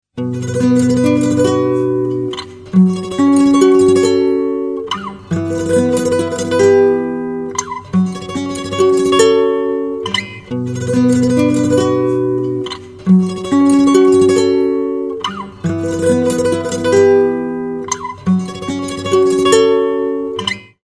زنگ موبایل
رینگتون آرام و بیکلام